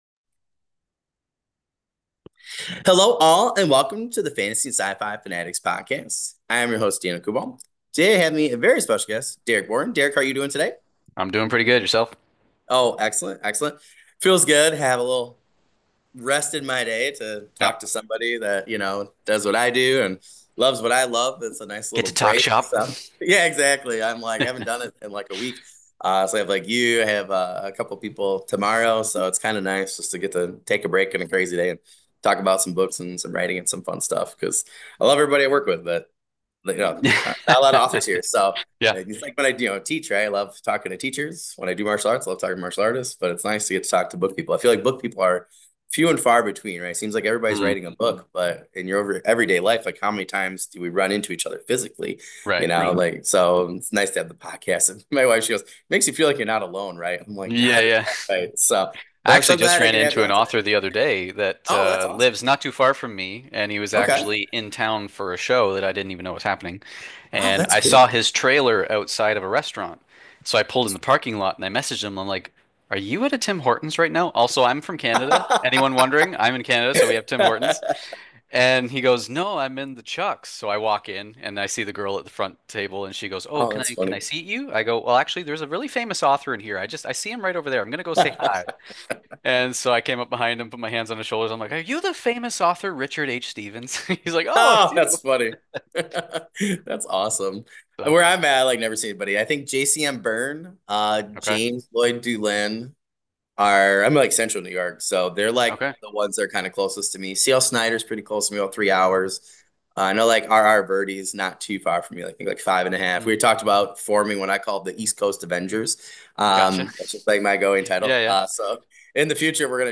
We will be interviewing different authors, writers, artists, and other members of the fantasy and sci-fi communities.